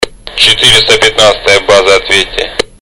• Качество: 320, Stereo
рация